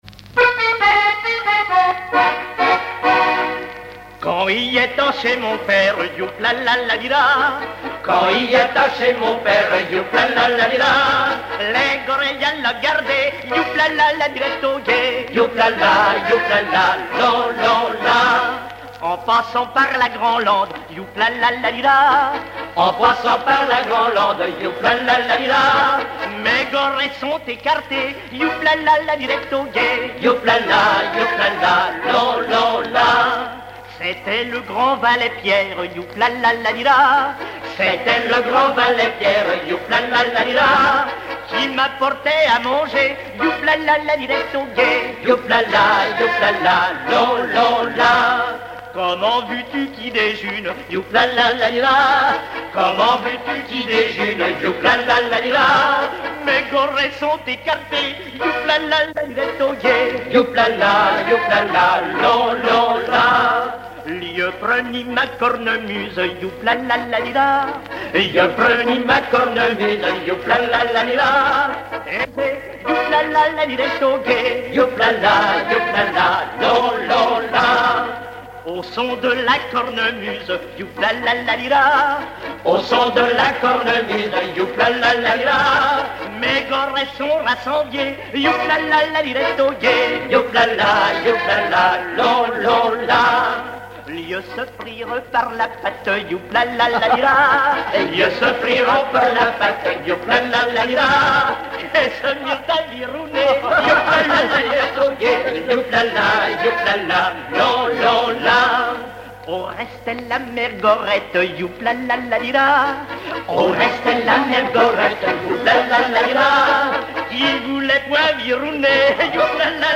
Langue Patois local
Genre laisse
Catégorie Pièce musicale inédite